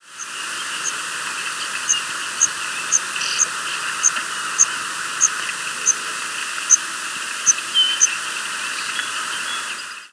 Yellow Warbler diurnal flight calls
Bird in flight with Song Sparrow singing in the background.